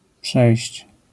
wymowa:
IPA[pʃɛjɕt͡ɕ], AS[pšei ̯ść], zjawiska fonetyczne: utr. dźw. ?/i